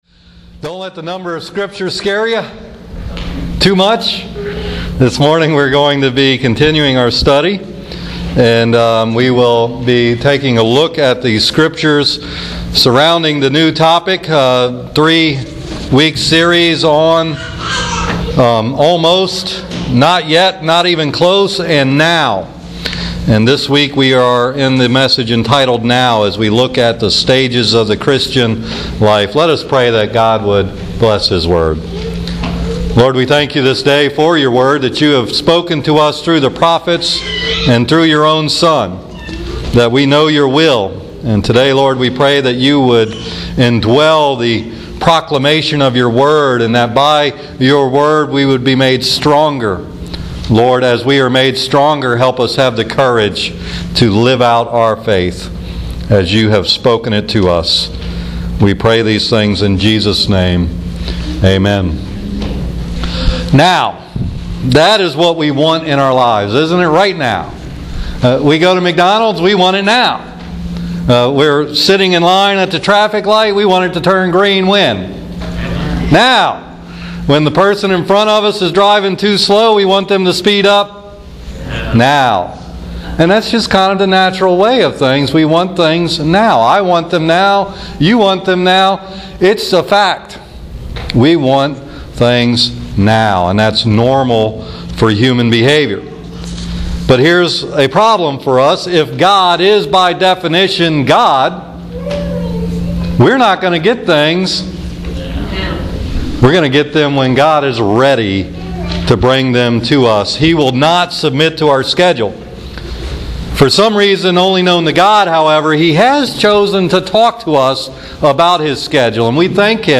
NOW! Final sermon in the Almost, Not Yet, and Now series